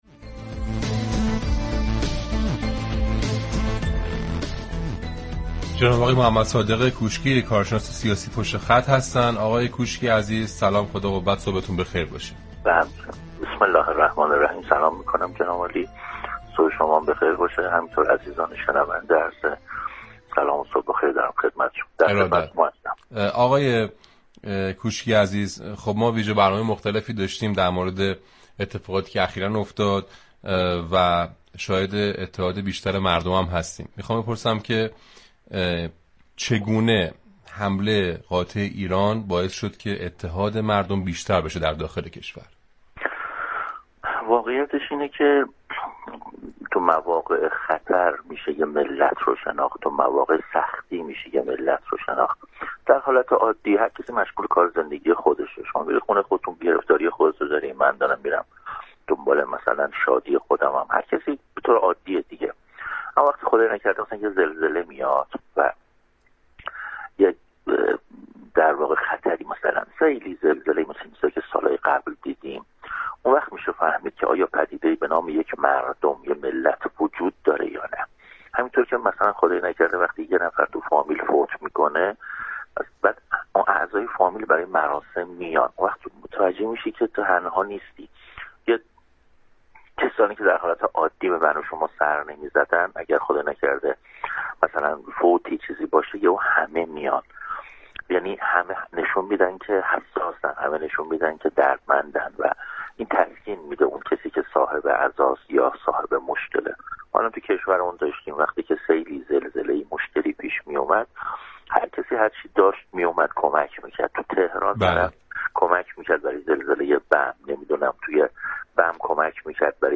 «رادیو صبا» در گفتگوی